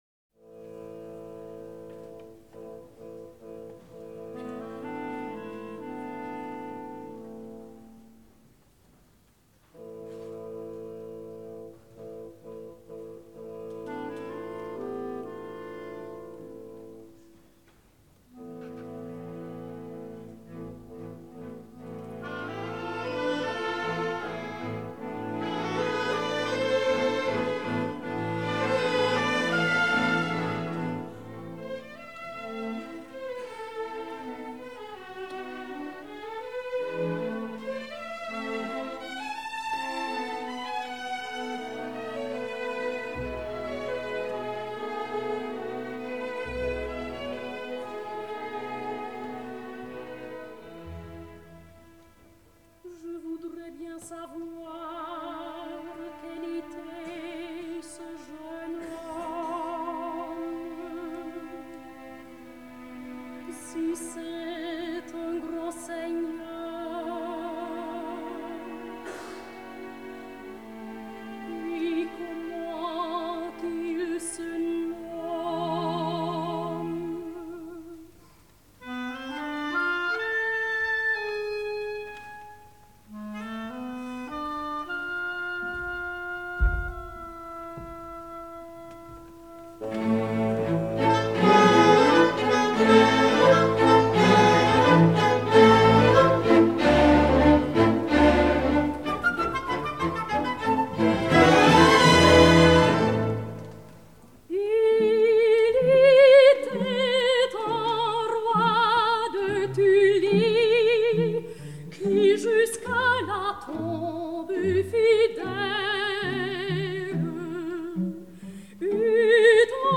Elly Ameling, Soprano. Charles Gounod: Air des bijoux (Marguerite’s aria, Act III).
Orchestre de la Suisse Romande.